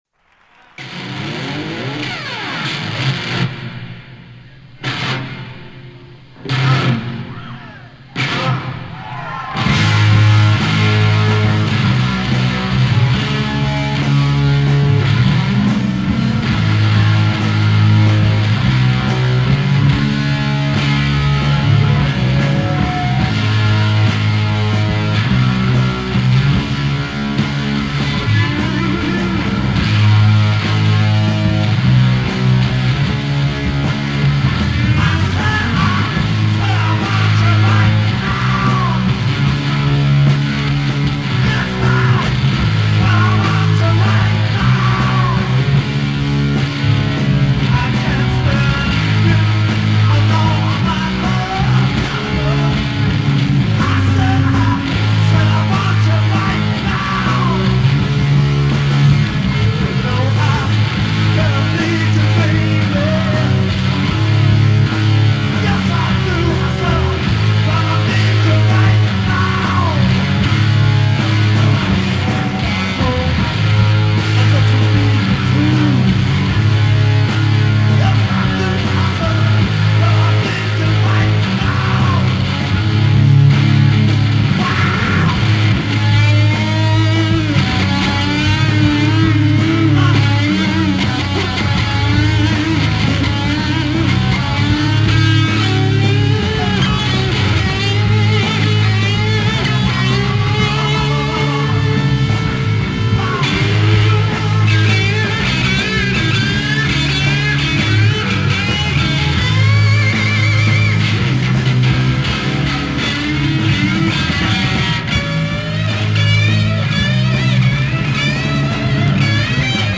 selecta ROCK 100% vinyles
1h15 de big ROCK!!!!!!!!!!